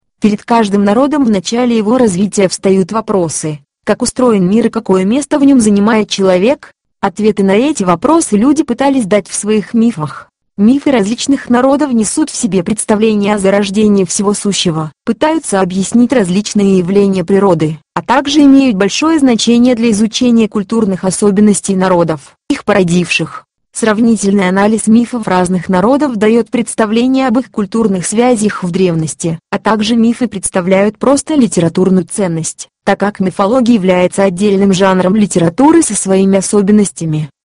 ScanSoft Milena - это одна из последних разработок в сфере русскоязычного синтеза речи, которая уже получила большое распространение на многих платформах, например, Mac OS, Symbian или iOS.
Синтезатор обладает женским голосом Милена с высоким качеством генерируемой речи и хорошой дикцией, который способен относительно правильно читать не только русский, но и английский текст.
Среди недостатков можно отметить достаточно малую скорость работы при использовании синтезатора для записи речевого потока в аудиофайл, а также периодически возникающие проблемы с правильной постановкой ударения в словах.